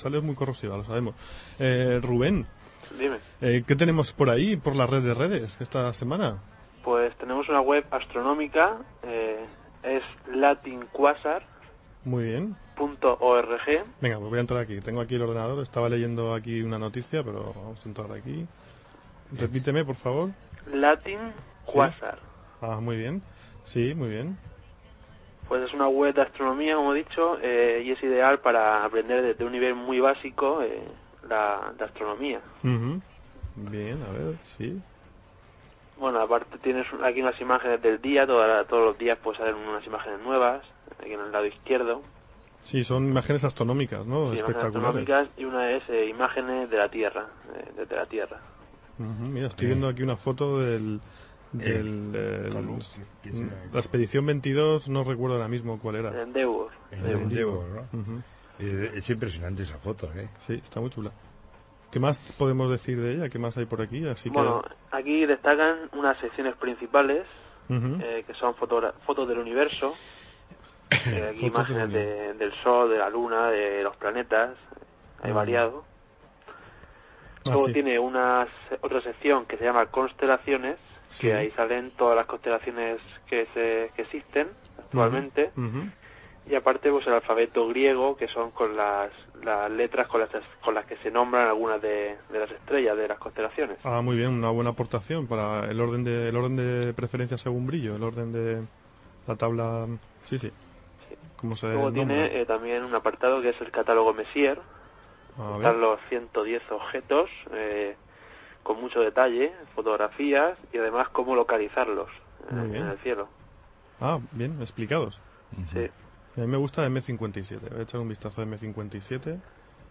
He recortado los fragmentos de la radio, aquí los tenéis.